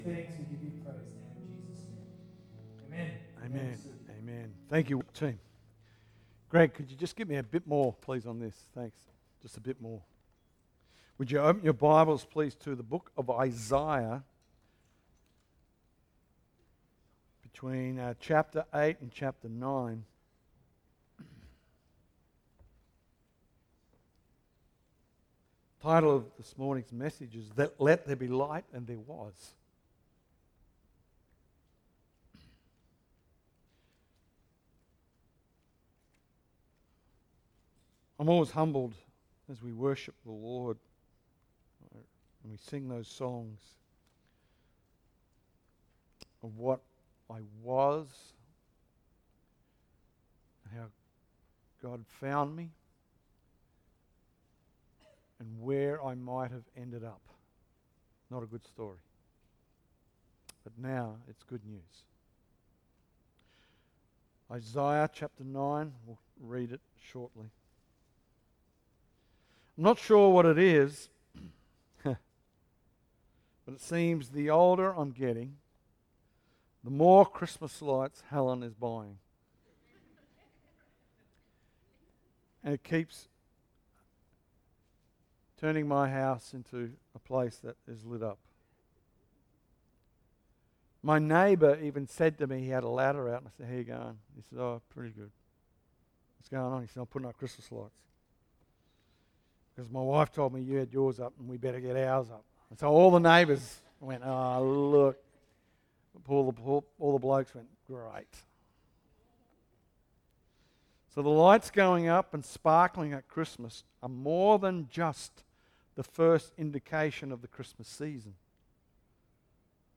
Message
Calvary Chapel Secret Harbour